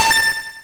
snd_buyitem.wav